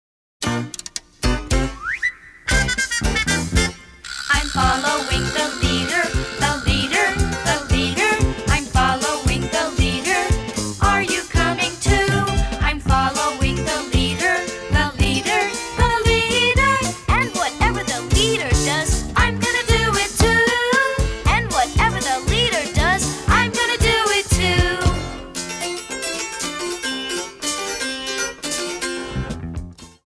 Music & Movement CDs and DVDs for Children